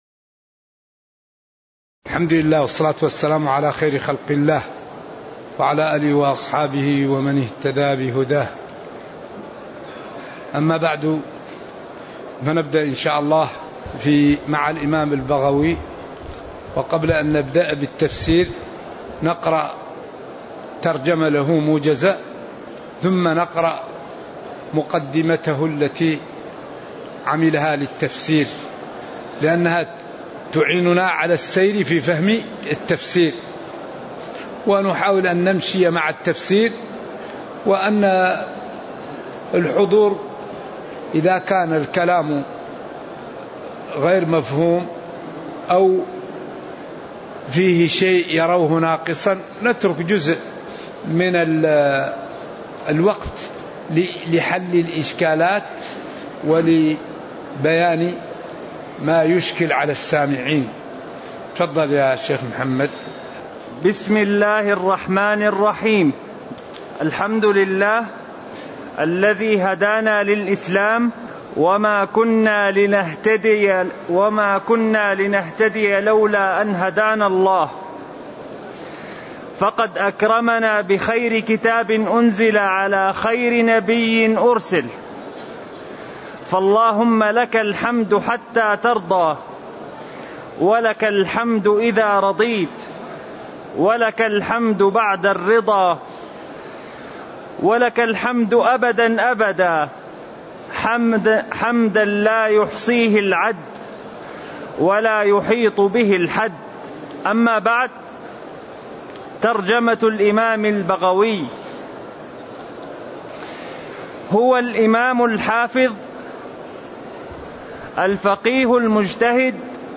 تاريخ النشر ٢٧ ربيع الأول ١٤٤٤ هـ المكان: المسجد النبوي الشيخ